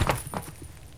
jump.wav